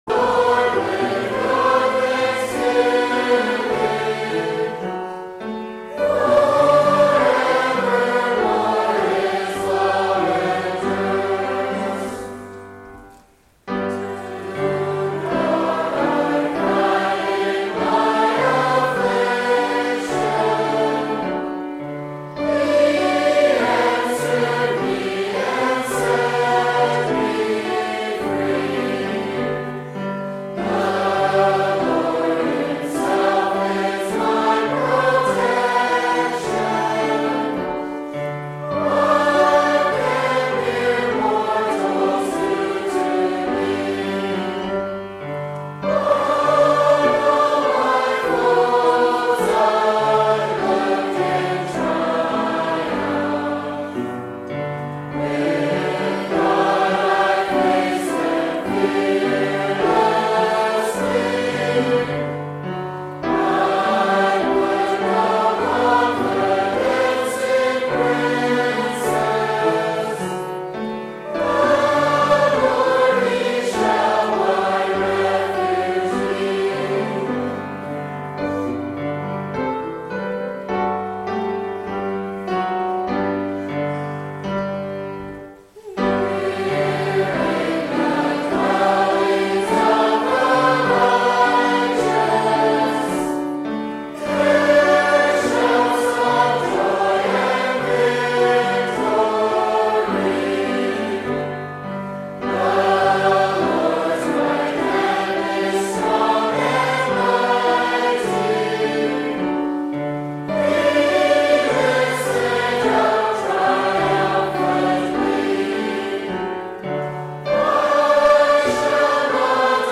Public Reading of Holy Scripture
Service Type: Sunday Afternoon